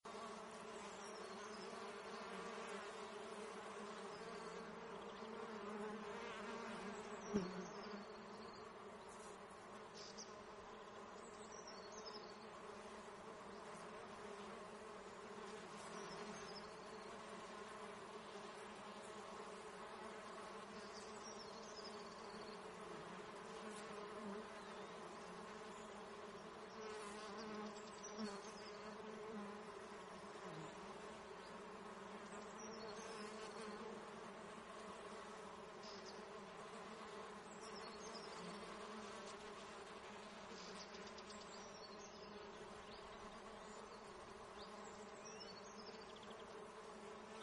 Duizenden bijen zijn aangelokt door de geur van honing en vullen de lucht met machtig gezoem.
bijen3.mp3